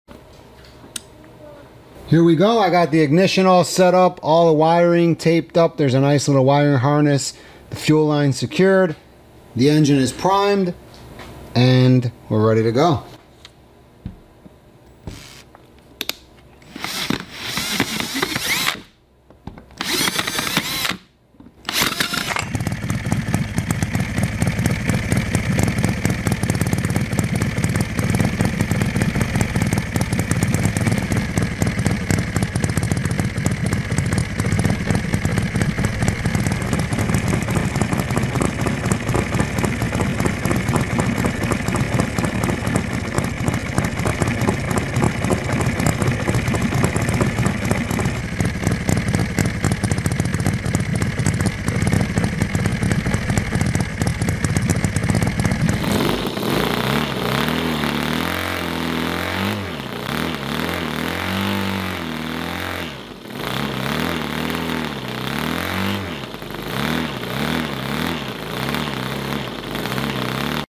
Mini Harley Davidson Panhead Engine sound effects free download
Mini Harley Davidson Panhead Engine (Sounds Like the Real Thing)